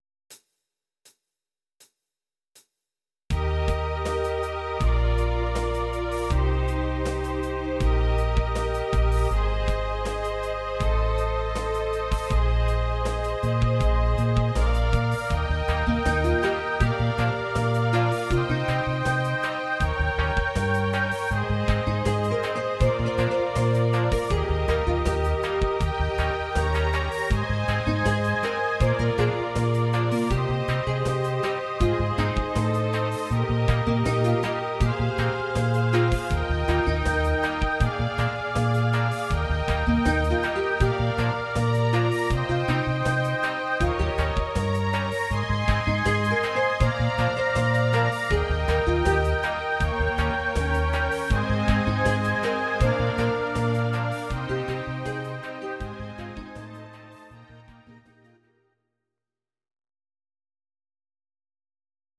Audio Recordings based on Midi-files
Pop, Rock, 1980s